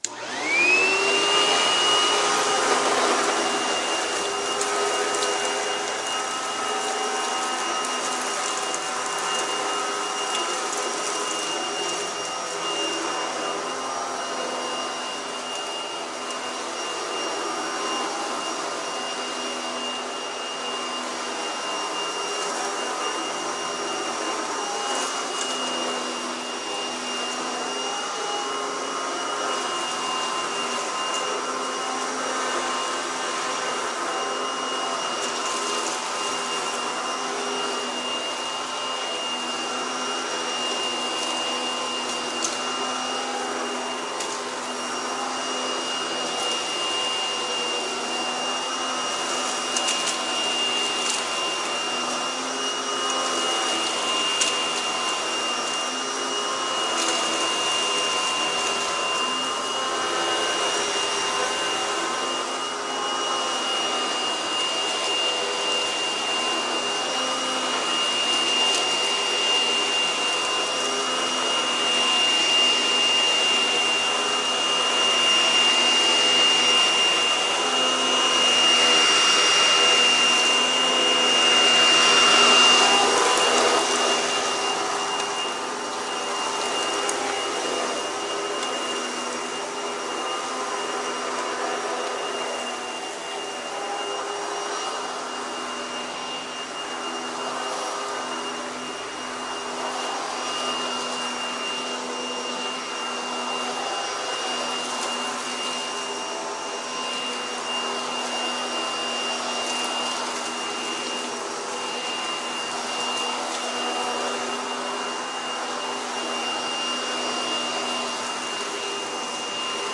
清洁 " 真空 3
在Android三星手机上录制了录音机。
您可以听到在此录音中被吸起的沙子和碎片。